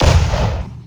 Pzh2000, BMP 20mm, T-80 firing...
I've had to do a lot of manipulating to make PZH and BMP sounds to be decent, still not sure about them.
pzh2000.wav